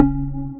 [BASS] Mustard.wav